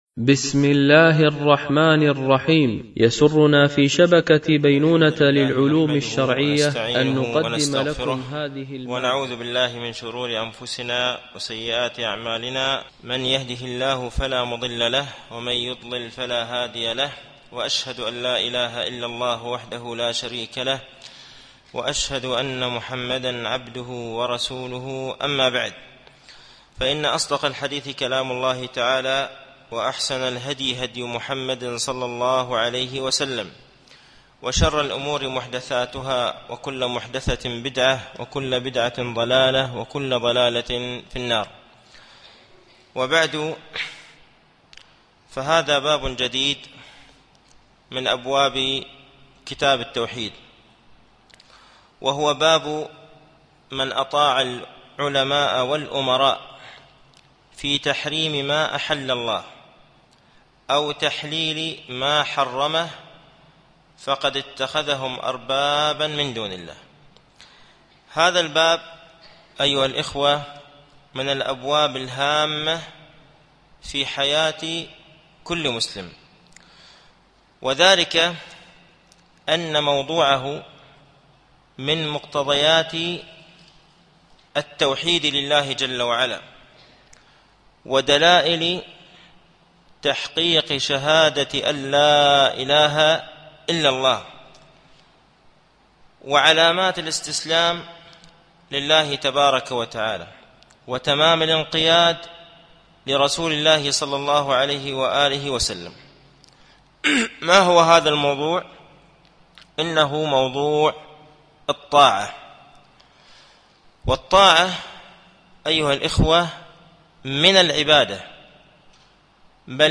التعليق على القول المفيد على كتاب التوحيد ـ الدرس العشرون بعد المئة